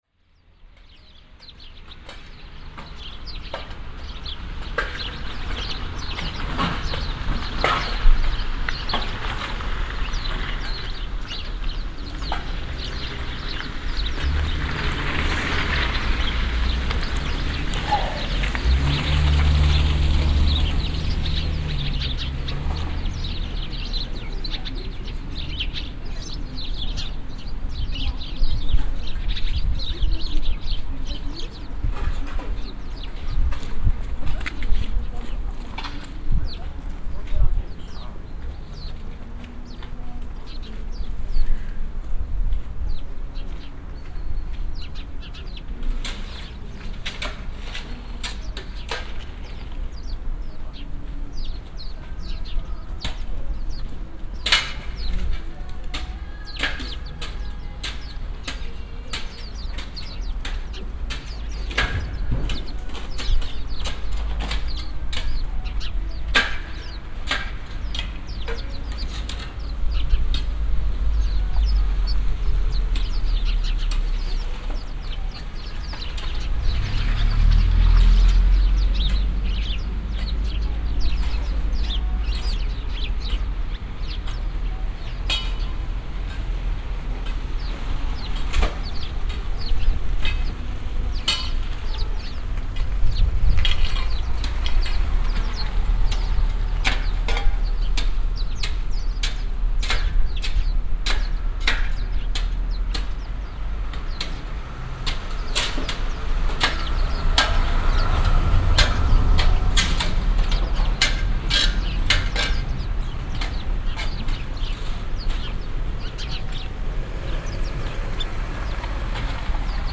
Micrófono binaural
Esta gravación foi realizada en Tallin (Estonia) e nela se pode escoitar o son nunha praza en inverno, as palas apartando a neve reverberando na praza e os paxaros cantando porque hai algo de sol.
Palas_pajaros_binaural.mp3